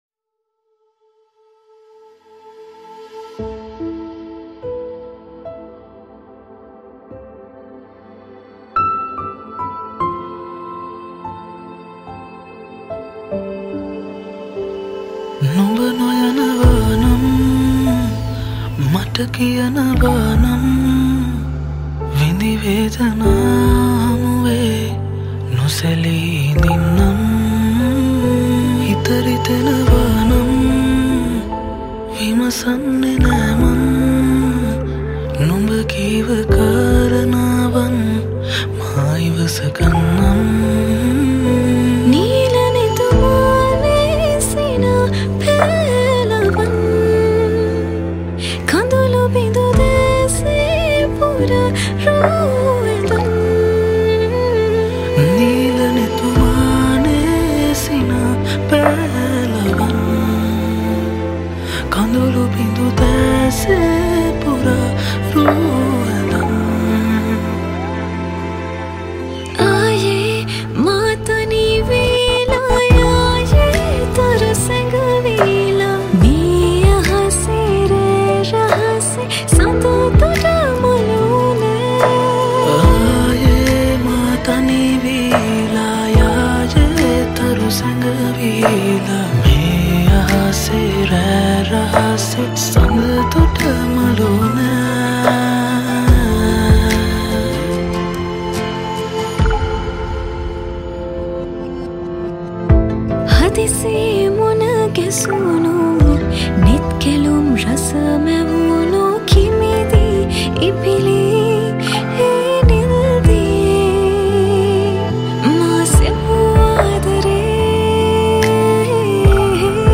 Releted Files Of Sinhala Mashup Songs